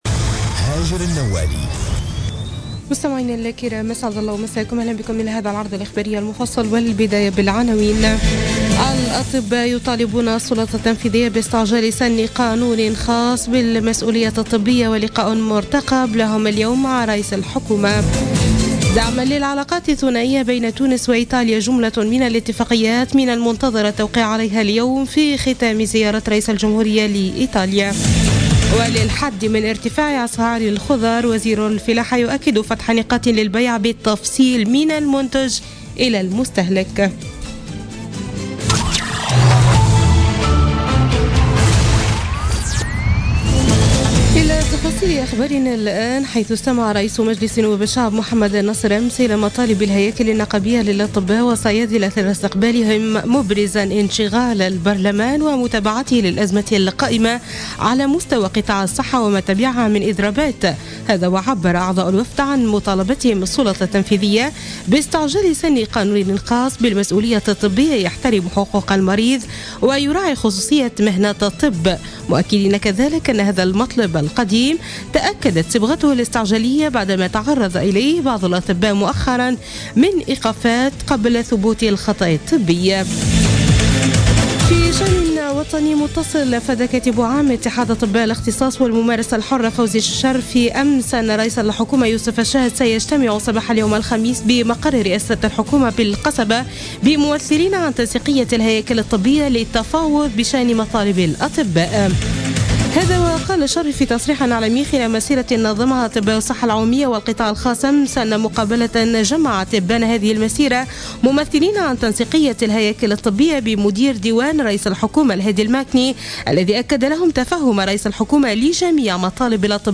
نشرة أخبار منتصف الليل ليوم الخميس 9 فيفري 2017